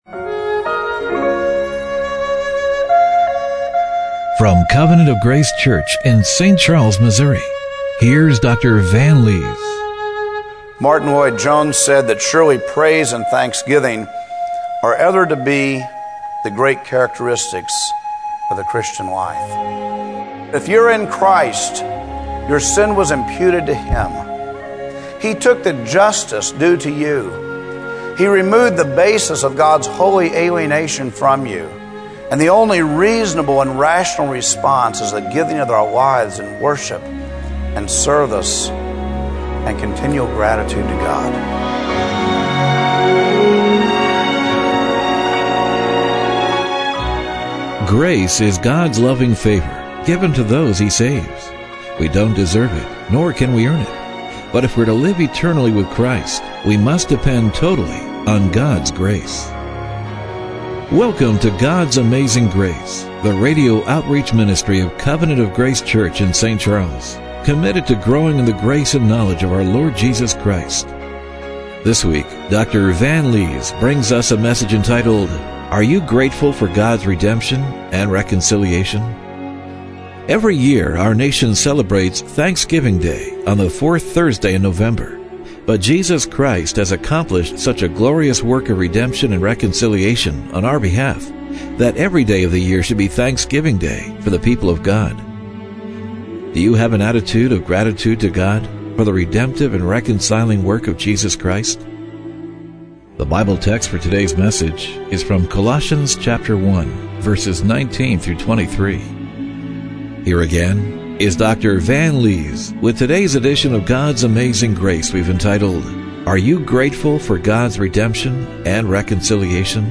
Colossians 1:19-23 Service Type: Radio Broadcast Do you have an attitude of gratitude to God